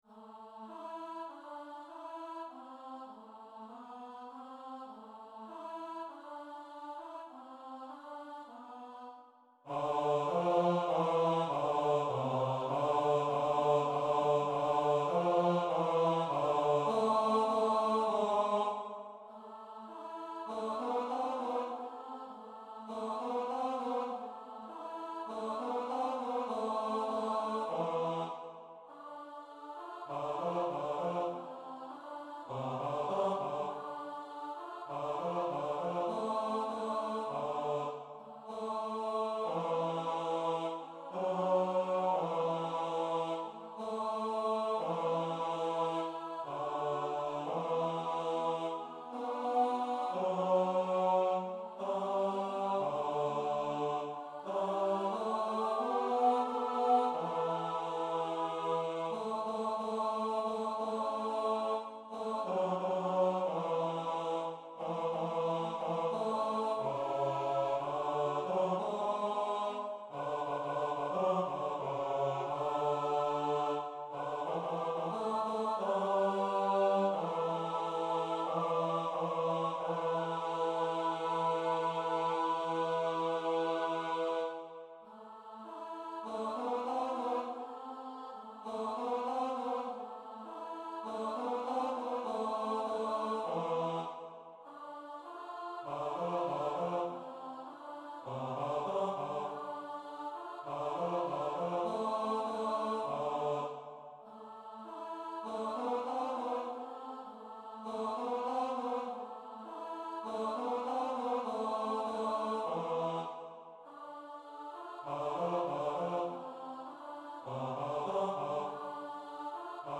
Take Pride Baritone | Ipswich Hospital Community Choir
Take-Pride-Baritone.mp3